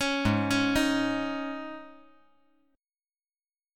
Listen to Absus4 strummed